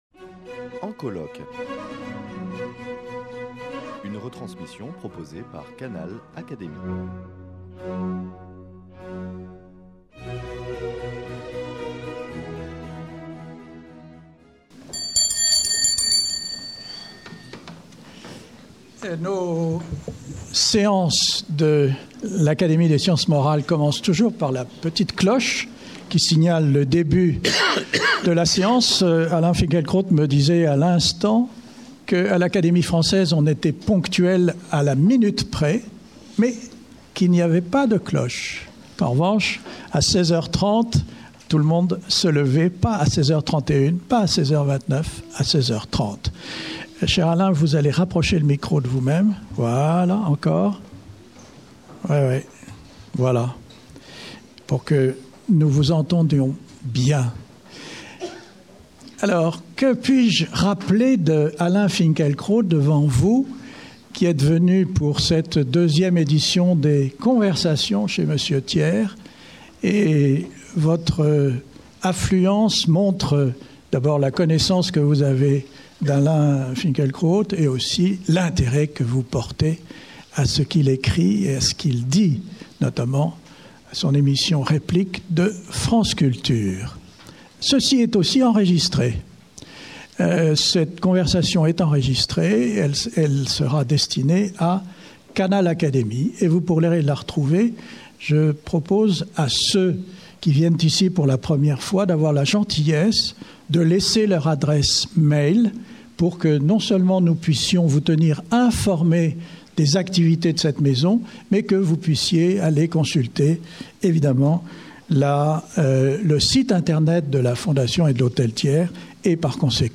Lors d’une séance sous la Coupole, Erik Orsenna, de l’Académie française évoque la figure de Louis Pasteur en la comparant à celle de Christophe Colomb, tandis que Maxime Schwartz, membre correspondant de l’Académie des sciences et ancien directeur général de l’Institut Pasteur, retrace le duel scientifique qui opposa Pasteur à Koch sur fond de rivalité franco-allemande.
Exposé sur Pasteur - Erik Orsenna